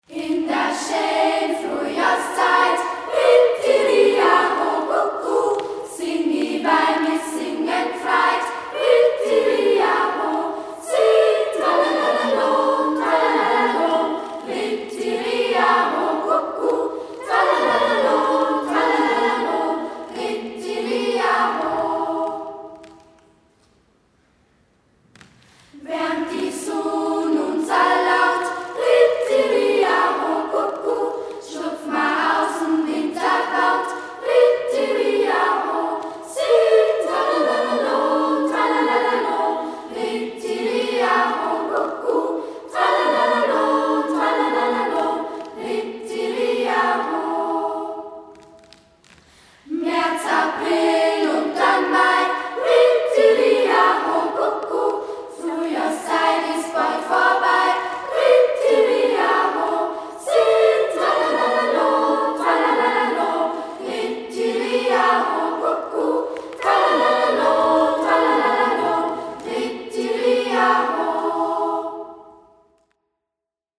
Die Schülerinnen der 2. und 3. Klasse trugen drei Lieder vor: